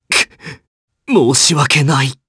Clause-Vox_Dead_jp.wav